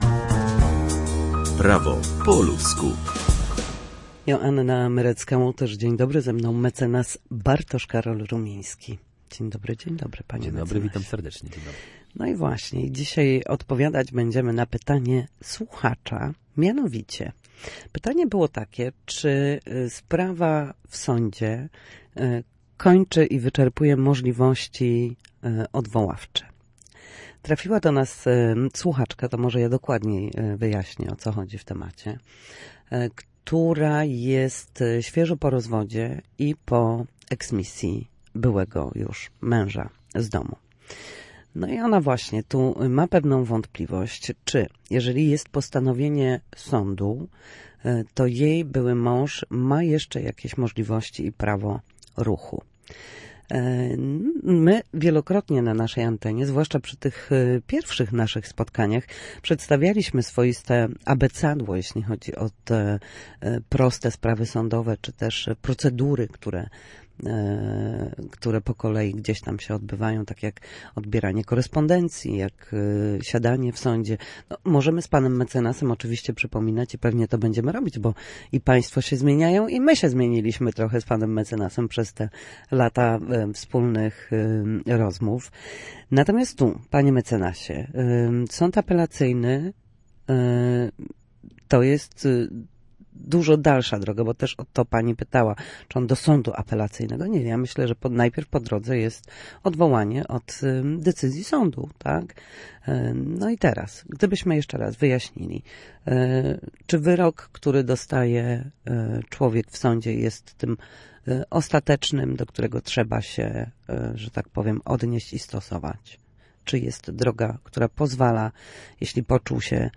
W każdy wtorek o godzinie 13:40 na antenie Studia Słupsk przybliżamy Państwu meandry prawa.
Nasi goście, prawnicy, odpowiadają na jedno pytanie dotyczące zachowania w sądzie lub podstawowych zagadnień prawniczych.